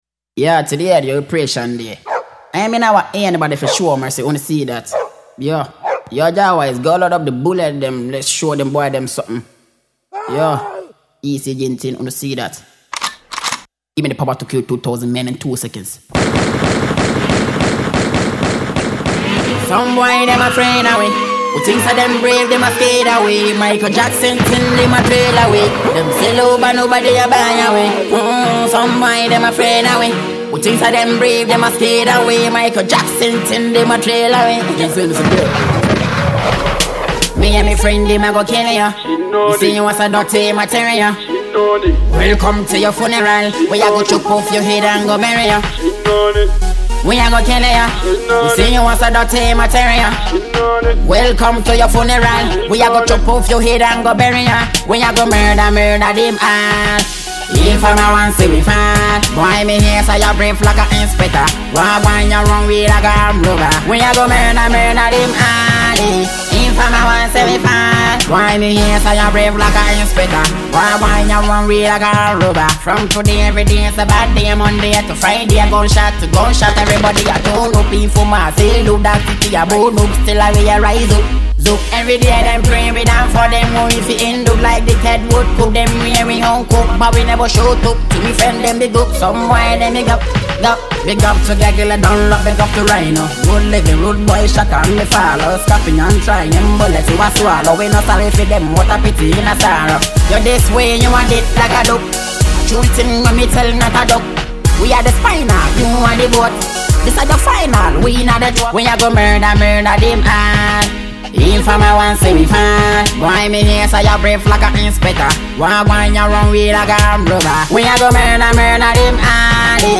Dancehall tune